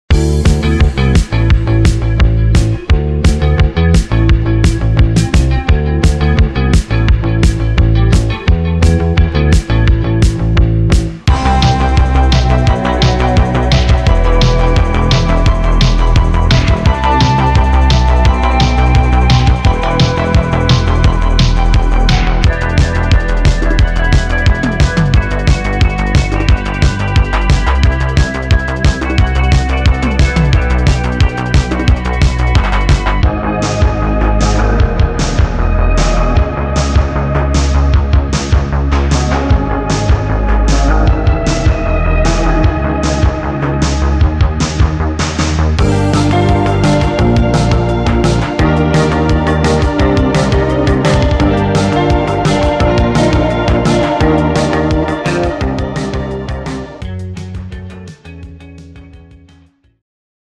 Niche